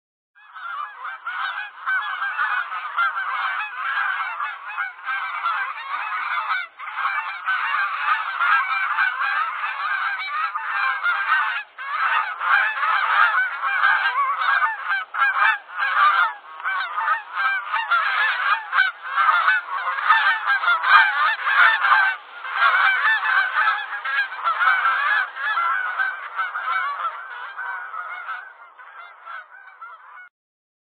Ziehende Kanadagänse [1.207 KB] - mp3
canadageese1320.mp3